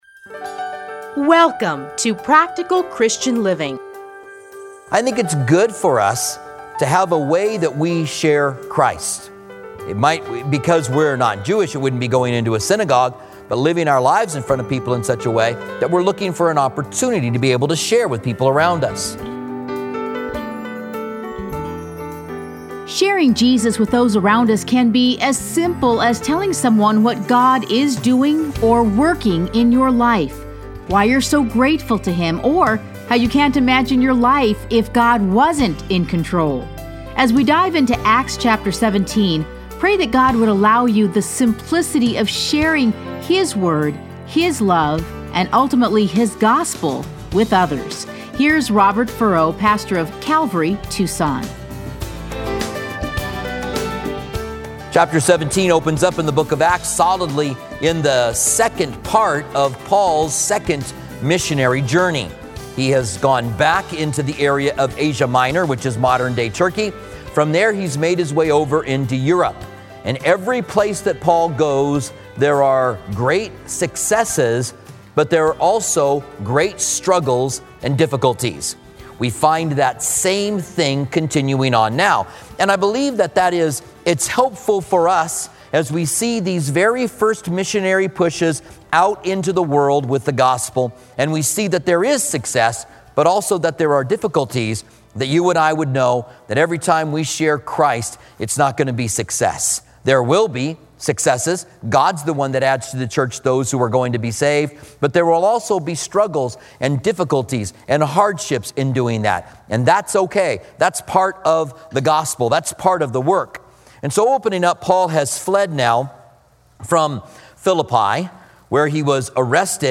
Listen to a teaching from Acts 17.